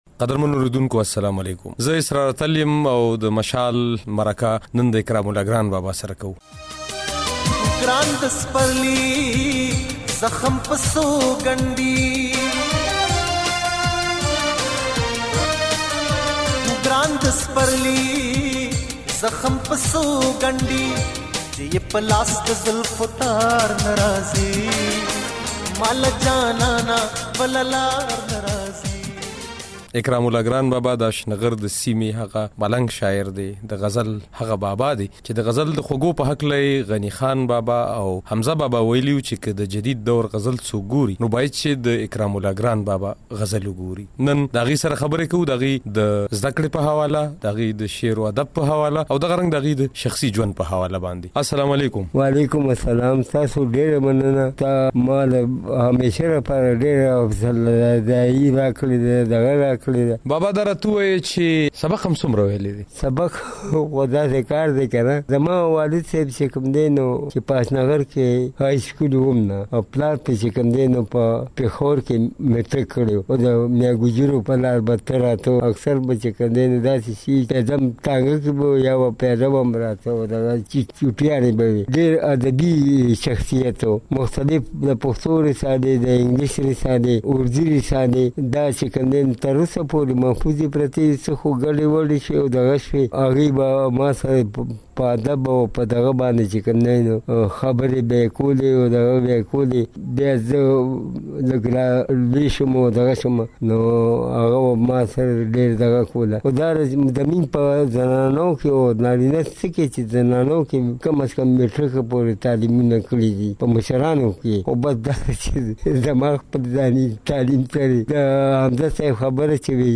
په مشال مرکه